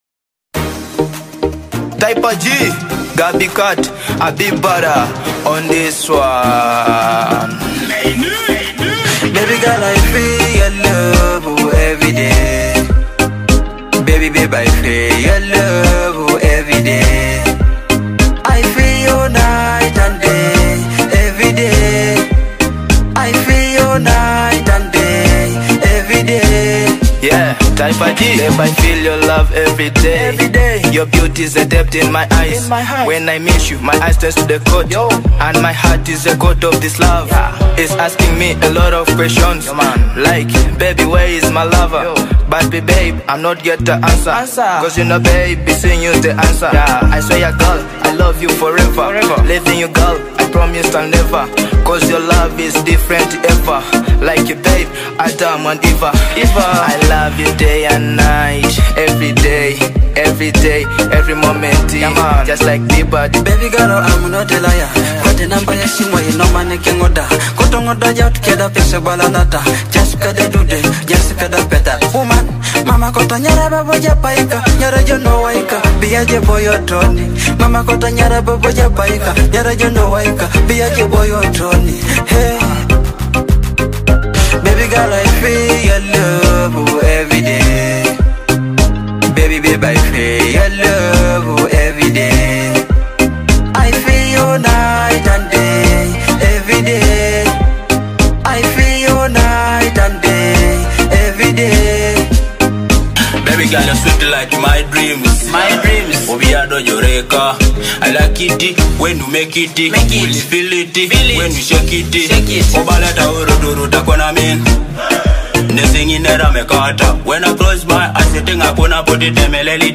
a soulful fusion of Teso rhythms and modern Afrobeat vibes.